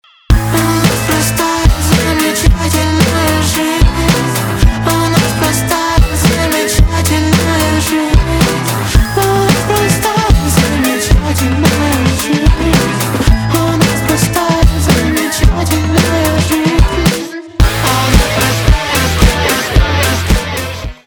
альтернатива
гитара , барабаны